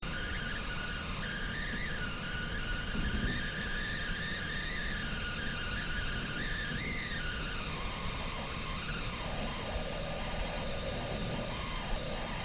This is the noise my desktop computer soundcard makes when doing nothing